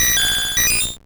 Cri de Porygon dans Pokémon Rouge et Bleu.